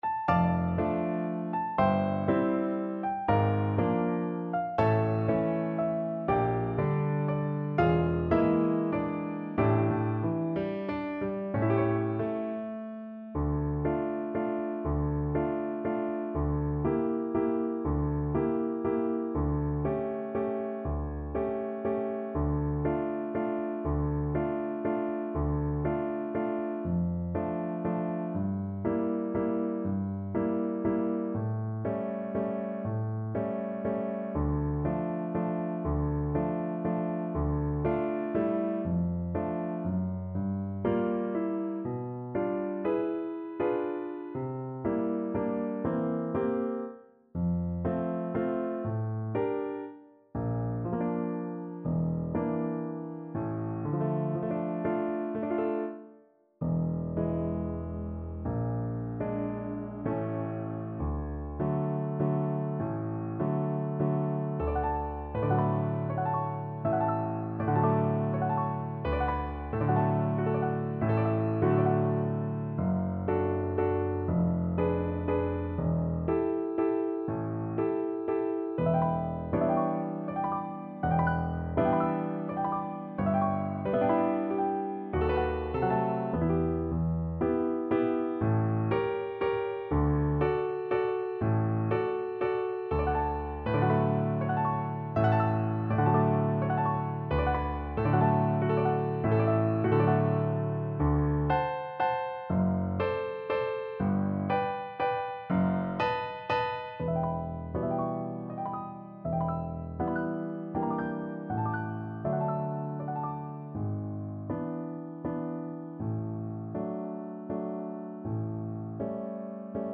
~ = 120 Lento
3/4 (View more 3/4 Music)